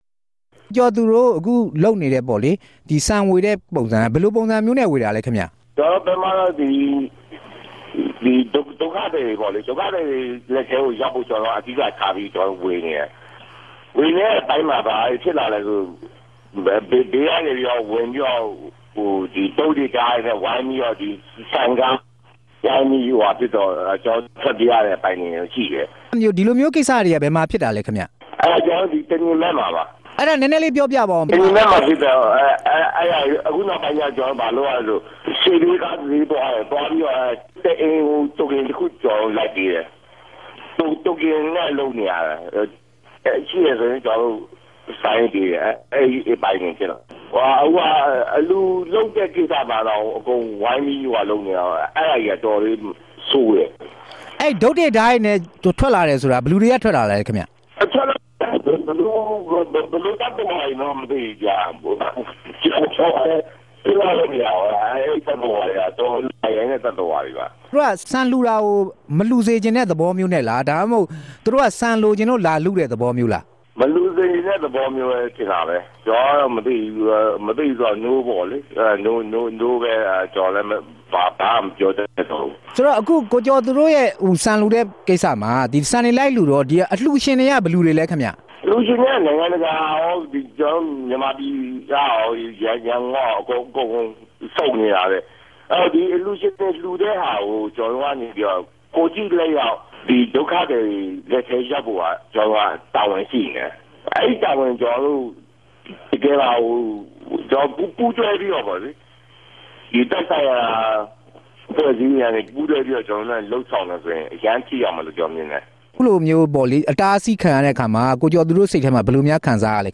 ဆက်သြယ် မေးူမန်းထားကို နားဆငိံိုင်ပၝတယ်။